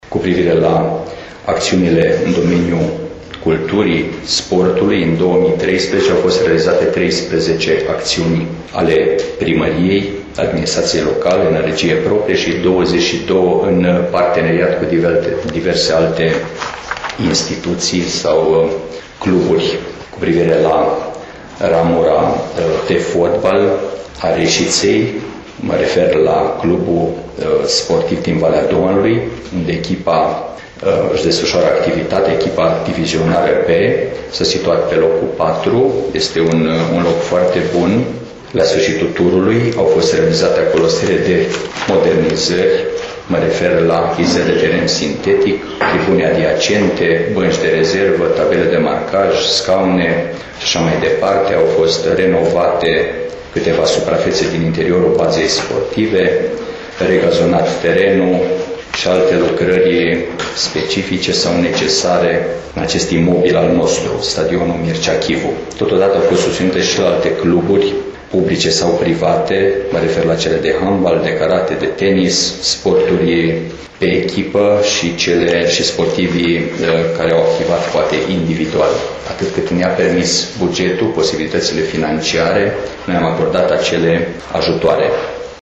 Toate sectoarele de activitate ale Primăriei Reşiţa au fost, aşadar, analizate la acest final de an de primarul Mihai Stepanescu. Acesta a făcut şi o sinteză a realizărilor din 2013 şi a explicat care sunt principalele acţiuni bifate de executivul reşiţean în domeniul culturii şi sportului: